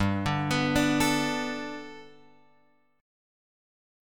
Gadd9 chord {3 5 x 4 3 5} chord